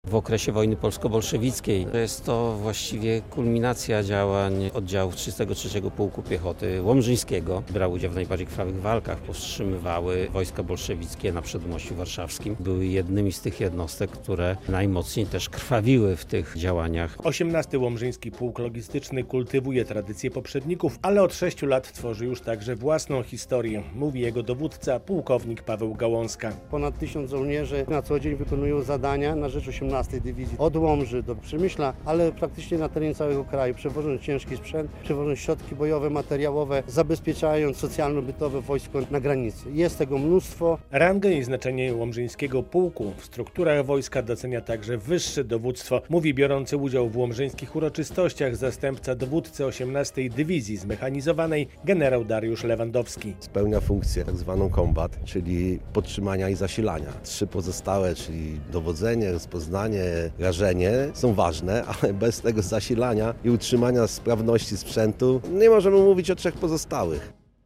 Święto 18. Łomżyńskiego Pułku Logistycznego - relacja